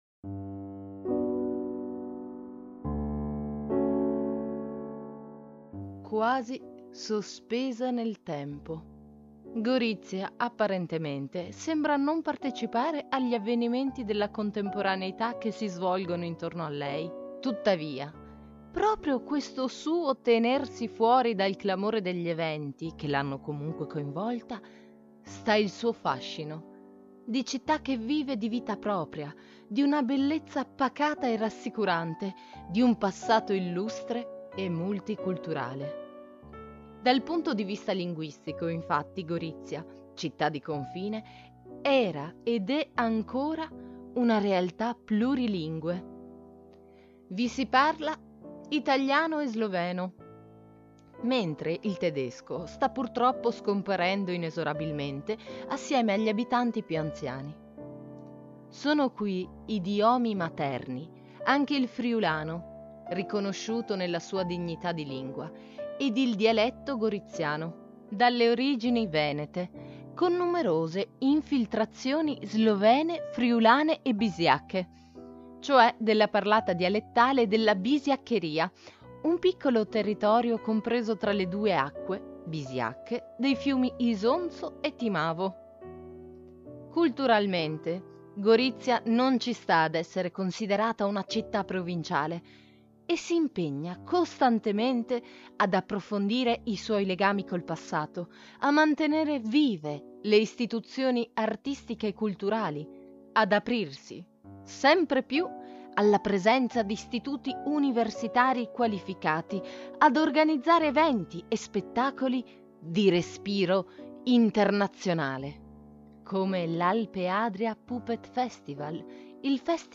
Gorizia-con-sottofondo.m4a